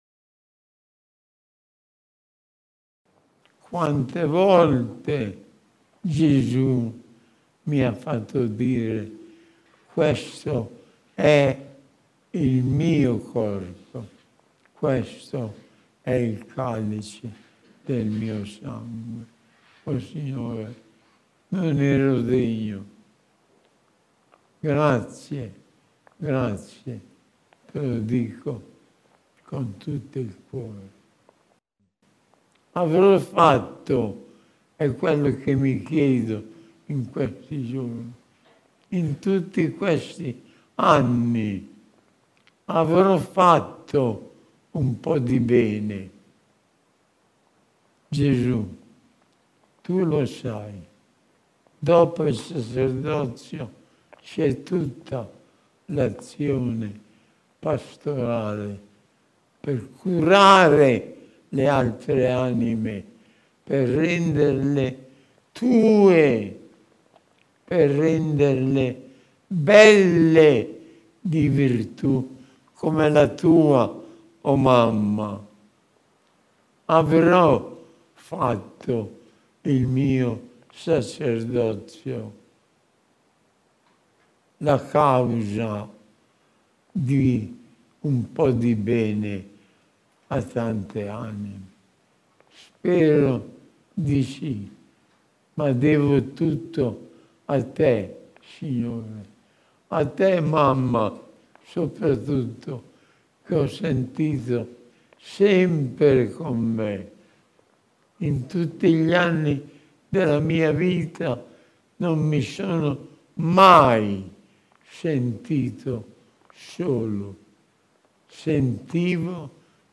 riascoltando l'ultima parte della sua omelia pronunciata durante la Santa Messa per i suoi 92 anni di vita